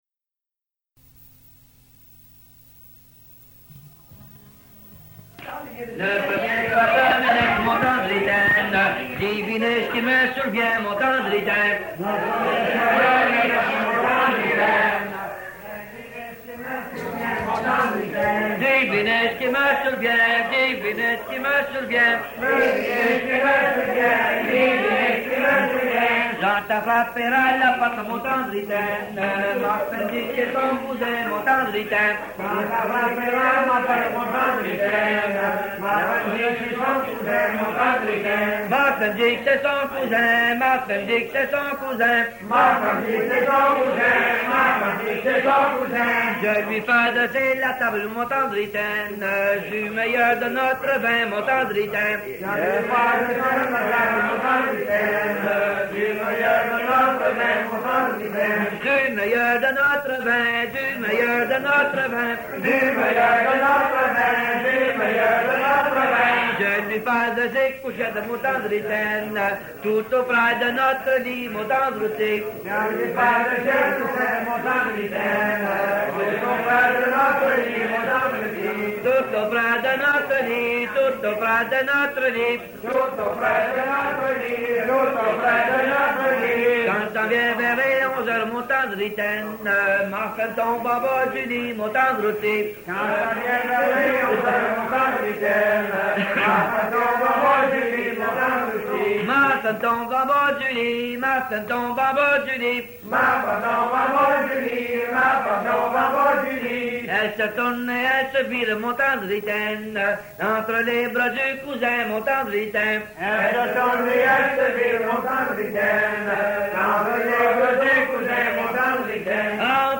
Folk Songs, French--New England
Franco-Americans--Music